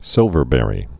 (sĭlvər-bĕrē)